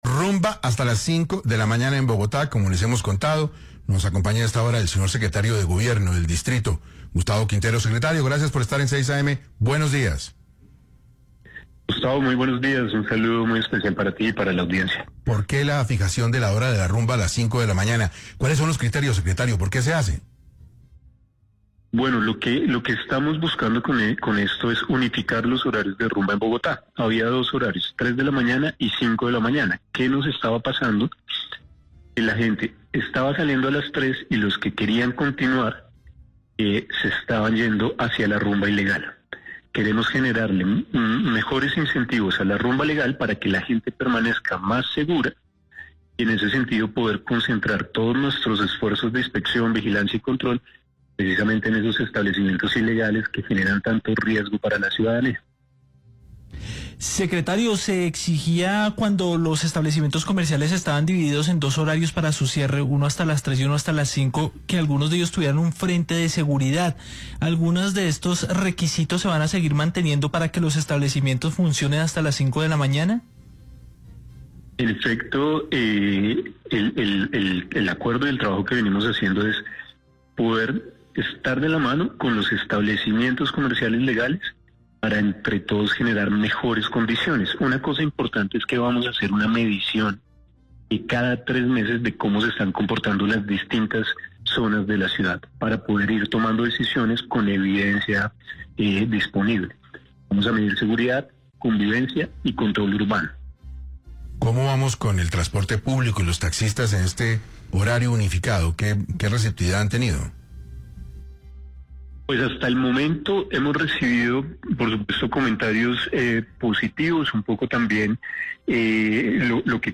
Luego del anuncio por parte de la Secretaría de Gobierno de Bogotá en la que se ordenó la ampliación del horario de rumba hasta las 5 de la mañana en la capital de la república, el secretario de Gobierno de Bogotá, Gustavo Quintero, detalló en 6AM de Caracol radio las razones que llevaron a la Alcaldía a tomar esta nueva medida.